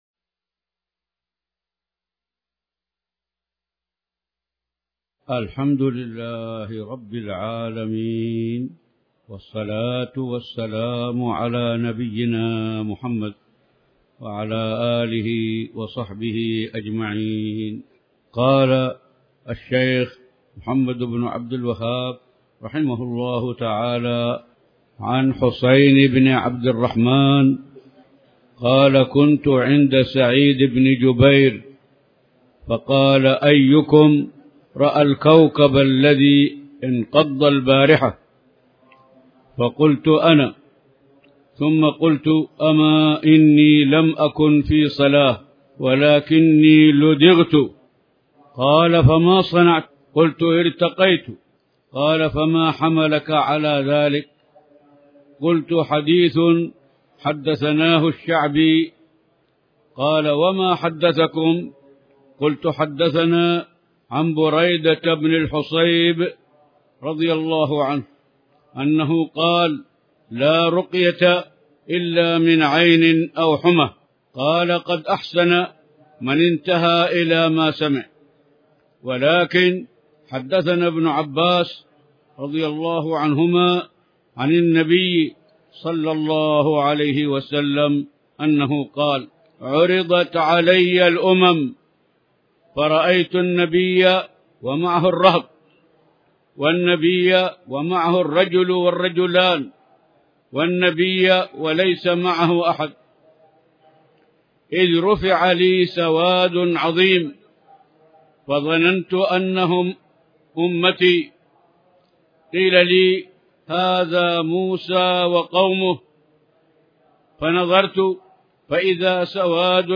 تاريخ النشر ٢٢ ذو الحجة ١٤٣٩ هـ المكان: المسجد الحرام الشيخ